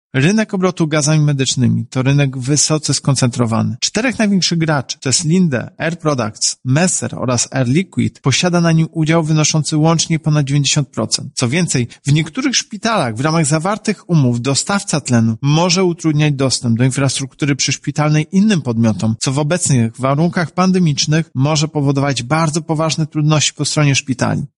• tłumaczy prezes UOKiK, Tomasz Chróstny i podkreśla, że właśnie takich sytuacji chce uniknąć przez wszczęte postępowanie.